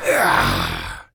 sceleton_atack1.ogg